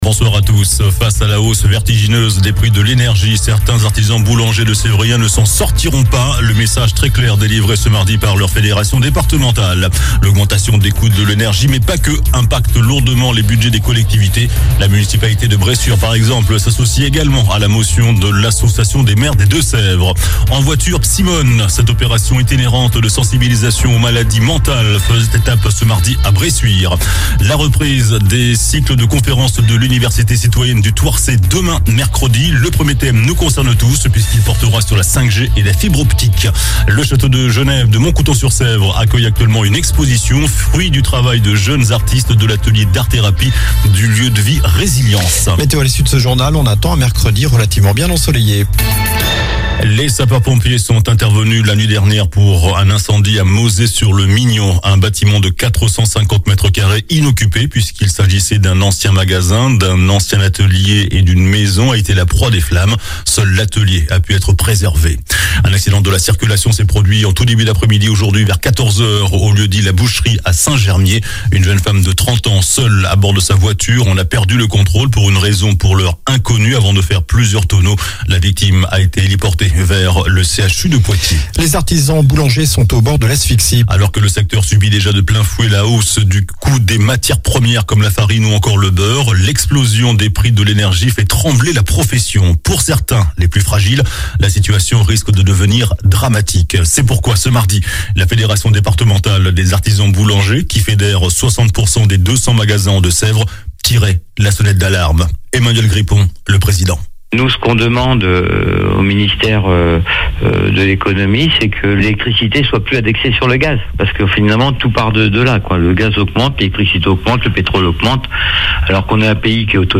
JOURANL DU MARDI 11 OCTOBRE ( SOIR )